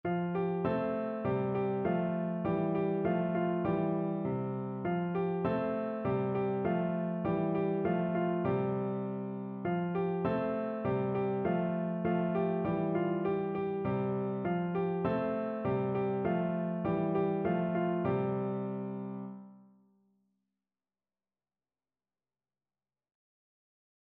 F major (Sounding Pitch) (View more F major Music for Piano )
2/4 (View more 2/4 Music)
Piano  (View more Easy Piano Music)
Traditional (View more Traditional Piano Music)
Kuma_San_PNO.mp3